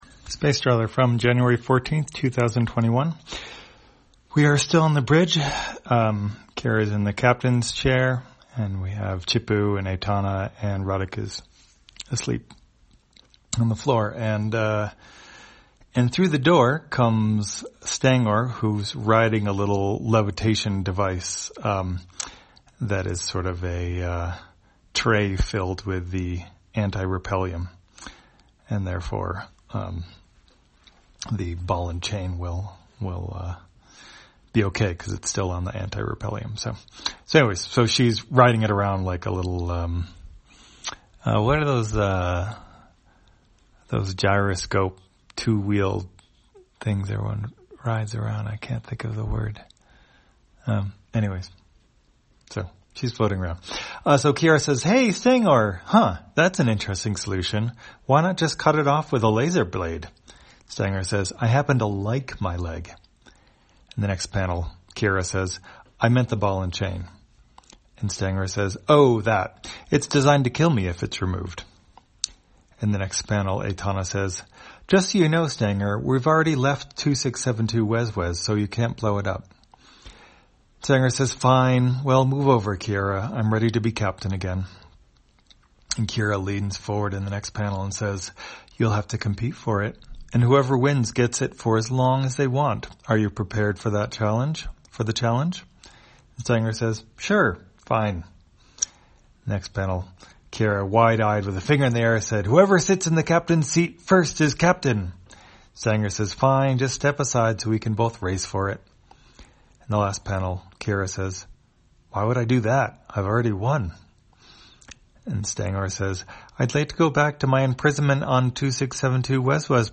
Spacetrawler, audio version For the blind or visually impaired, January 14, 2021.